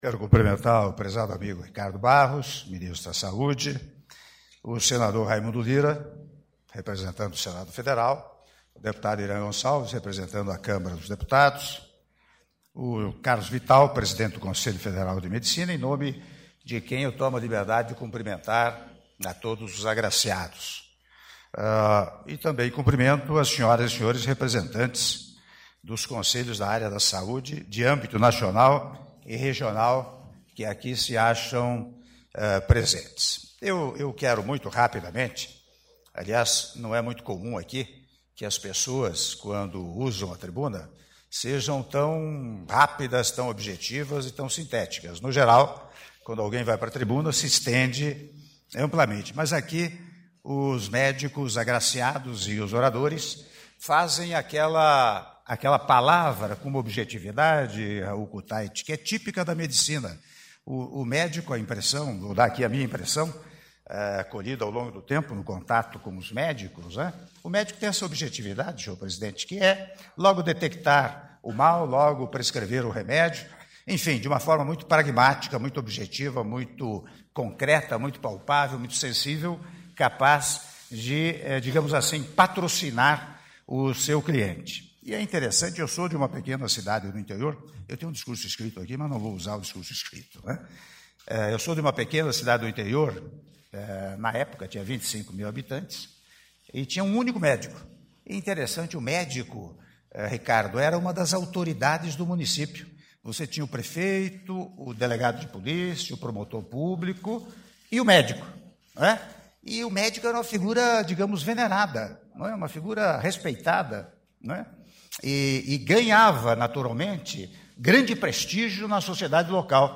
Áudio do discurso do Presidente da República, Michel Temer, na cerimônia de Imposição de Insígnias da Ordem do Mérito Médico - Brasília/DF- (05min38s)